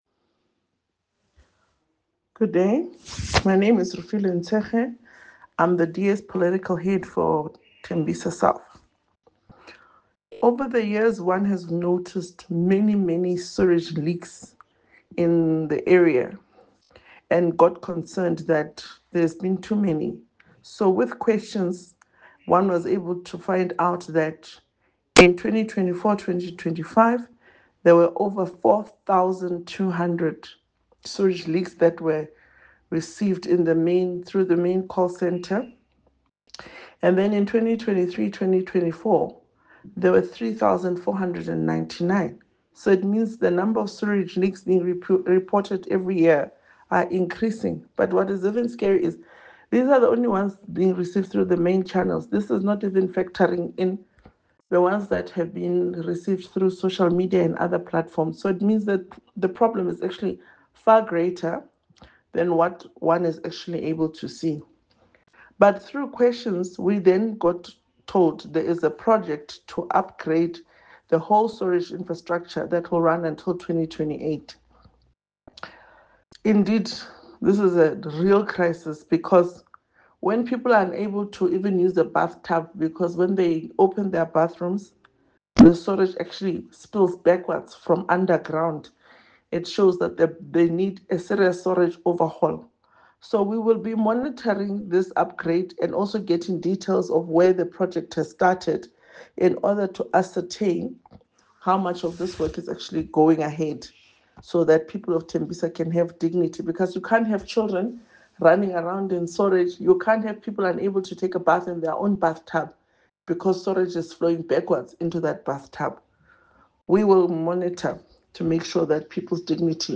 soundbite by Refiloe Nt’sekhe MPL.